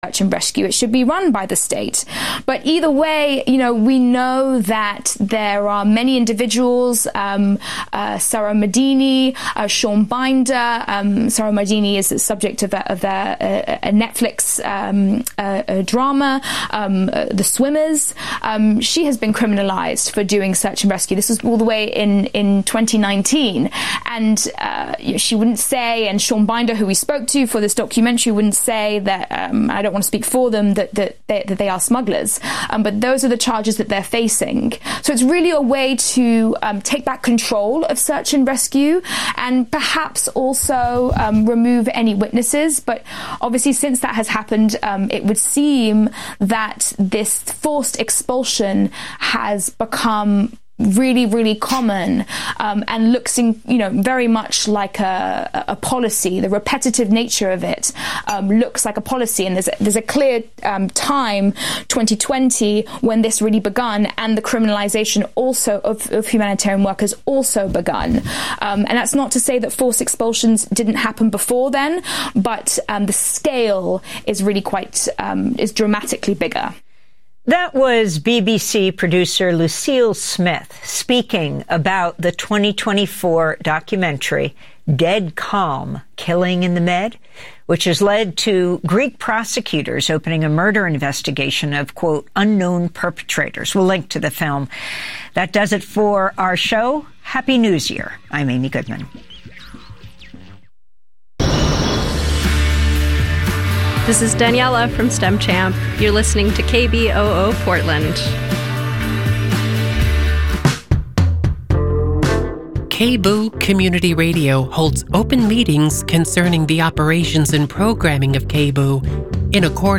On this episode of the KBOO Evening News: